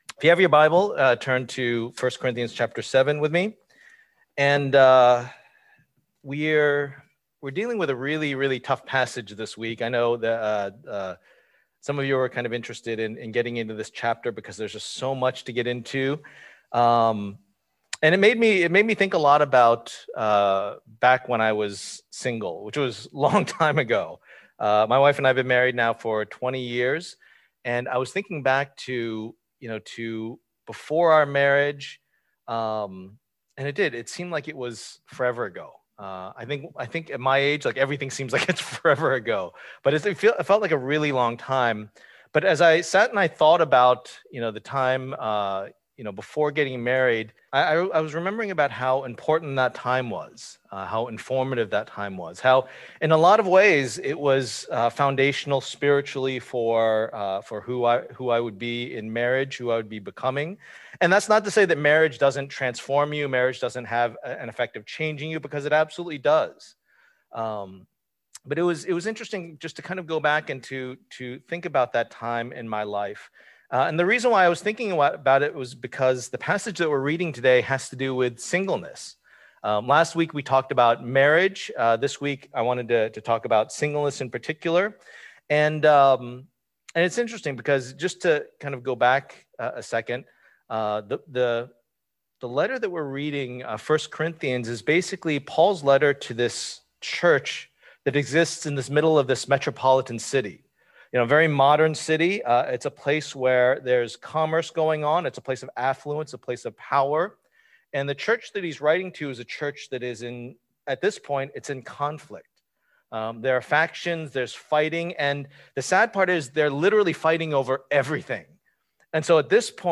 Passage: 1 Corinthians 7:25-40 Service Type: Lord's Day